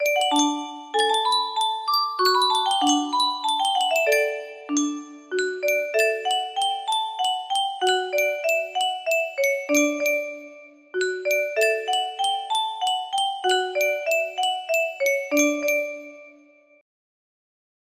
Yunsheng Music Box - Unknown Tune 1090 music box melody
Full range 60